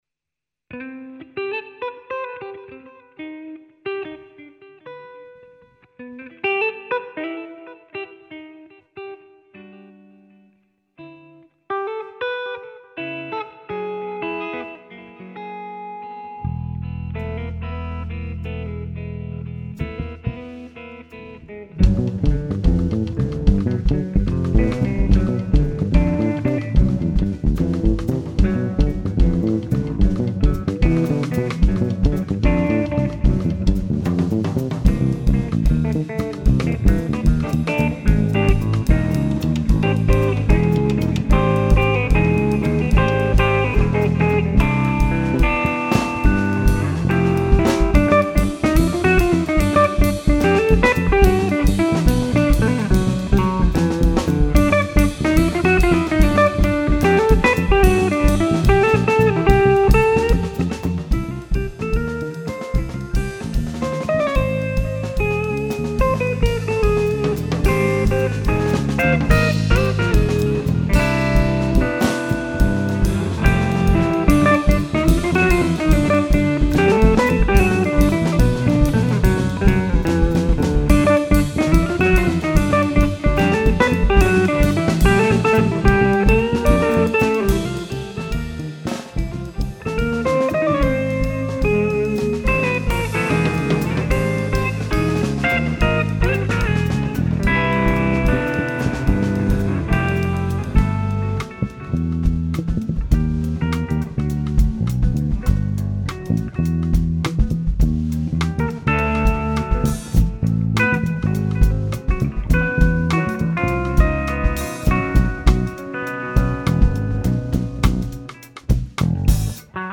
Nahráváno ve zkušebně do ZOOM recordéru-8stop, dohromady všechny nástroje, včetně samplu.
Fender JB AM Stand+TE aparát
Pěkná fusion záležitost a na zkušebnu celkem dobrý zvuk.